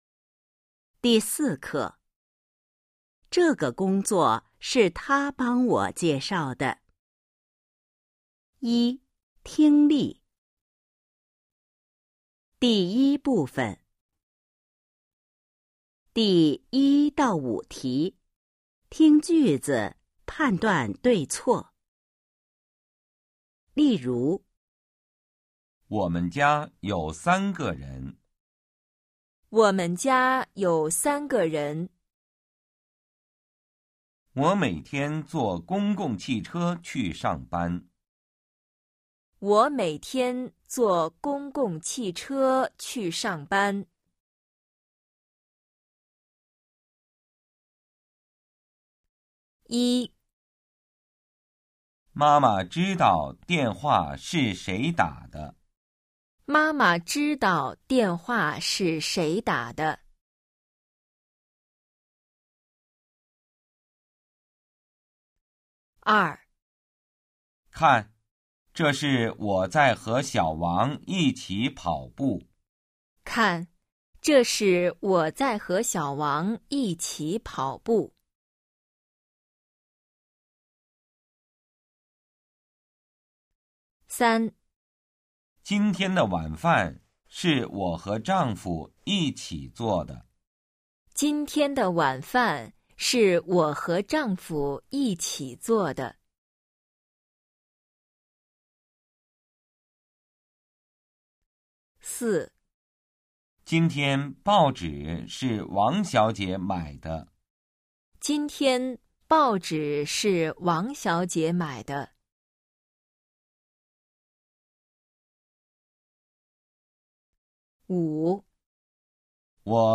一、听力 Phần nghe 🎧 04-1